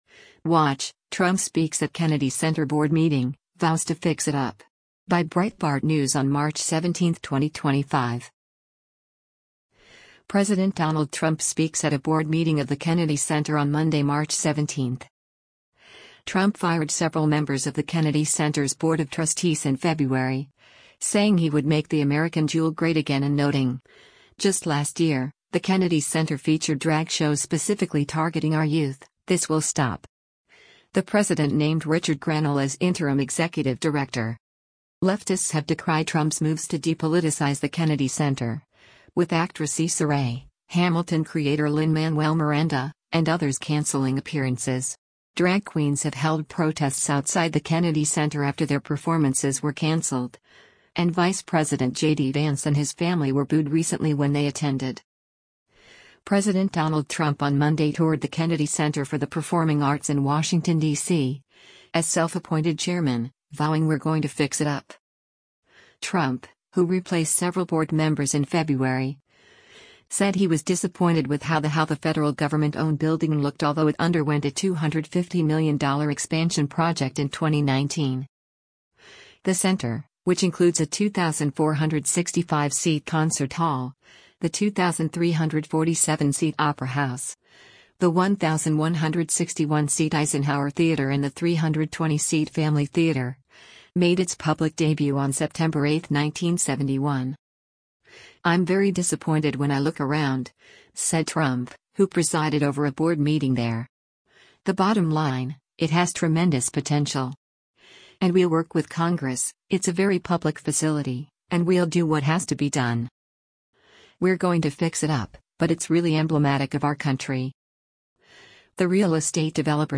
President Donald Trump speaks at a board meeting of the Kennedy Center on Monday, March 17.